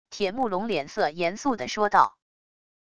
铁木龙脸色严肃的说道wav音频生成系统WAV Audio Player